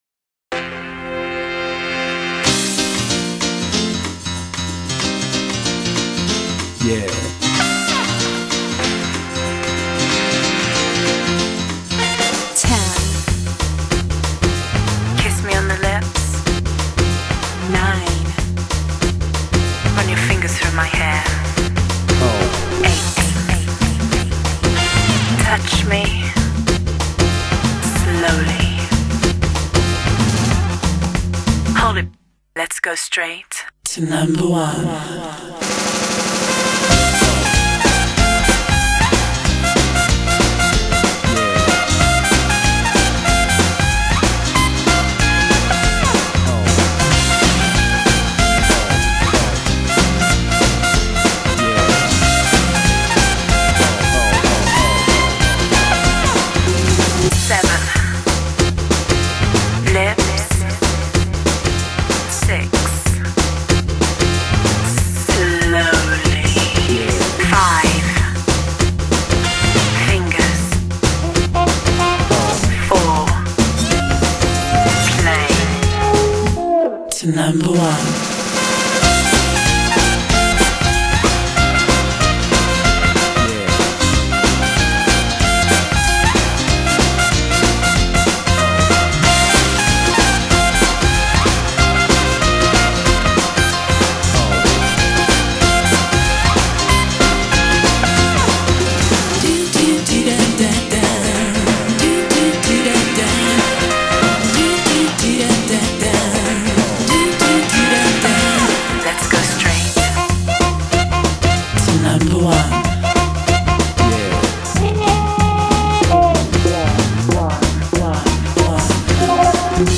Genre: Dance / Soul / Easy Listening ...
这是一套欧美交谊舞学校的教学和考试，比赛专用CD。
Jive (01:15:13)